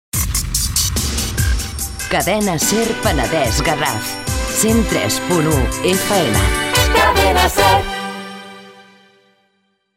Identificació i freqüència